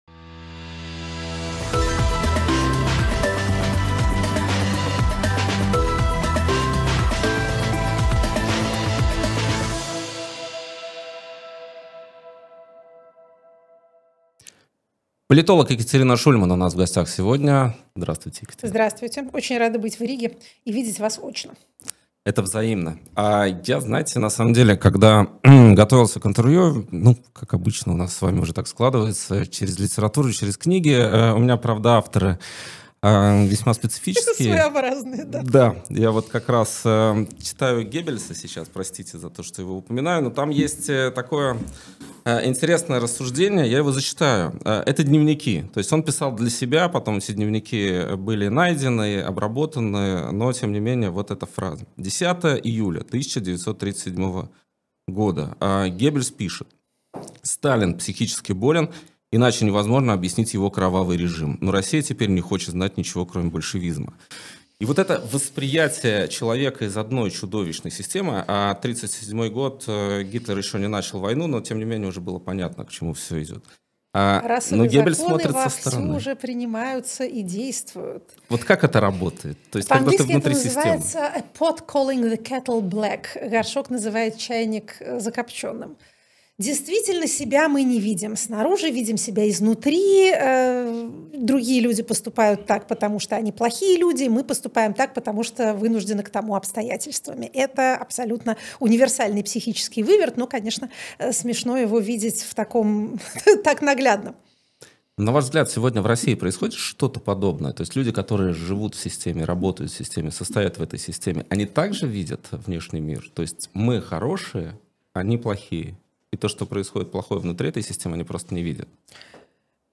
Екатерина Шульман политолог